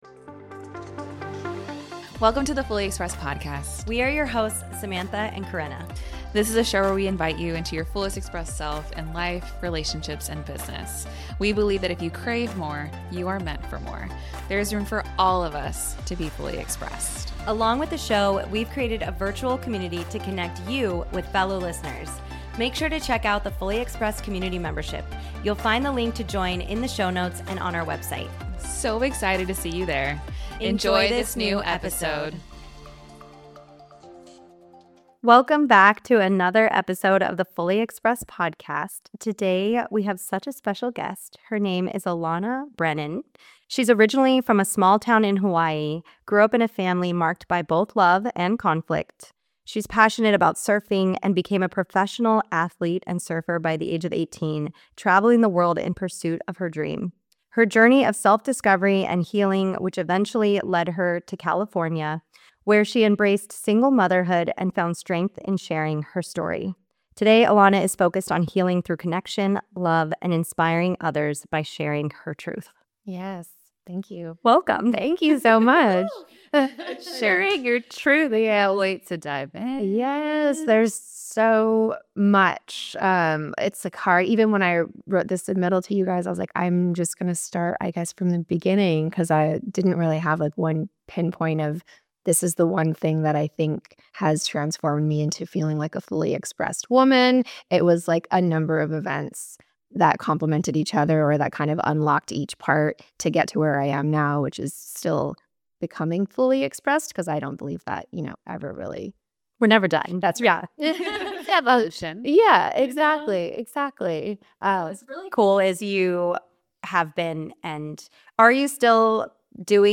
In this raw and deeply personal conversation